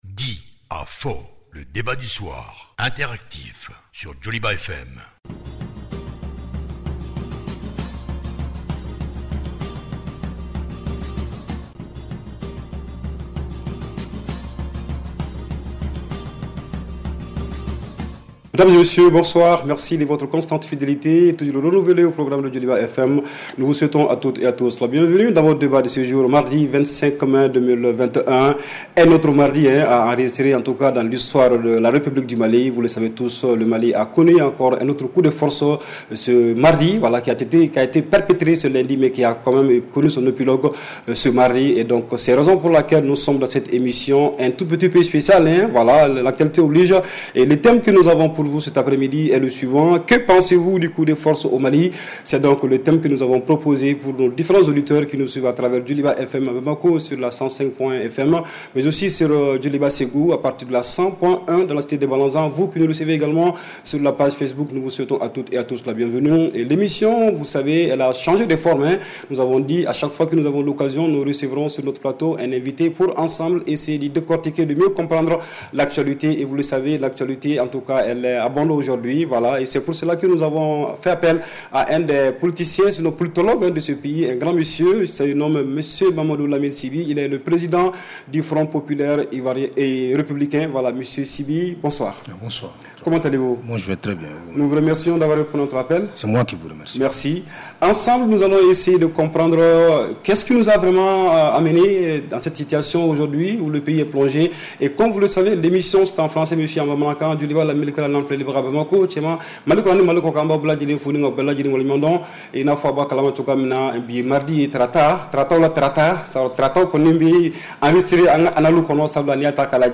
REPLAY 25/05 – « DIS ! » Le Débat Interactif du Soir